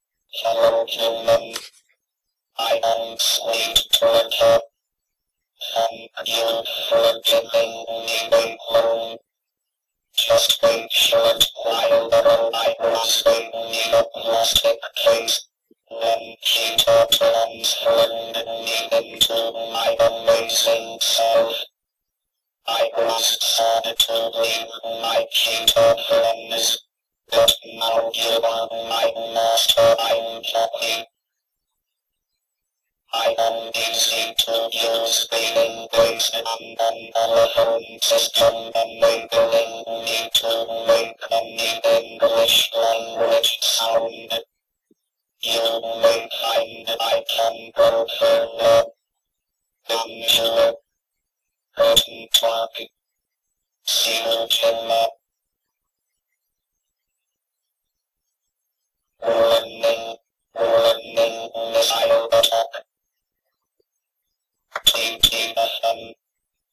Der Cheetah Sweet Talker war eine Sprachausgabe-Modul für den ZX Spectrum.
Über eine Liste von einzelnen Silben und Lauten, den Allophonen, die als DATA-Anweisungen ausgegeben werden, werden Worte und Sätze erzeugt, die mehr oder weniger verständlich sind. Das Paket enthielt ein Demoprogramm, das den User begrüßt und kurze Anweisungen erteilt.
Um überhaupt etwas verstehen zu können, wird das Tonsignal, bevor es auf den Verstärker geht, über einen Tiefpass geglättet.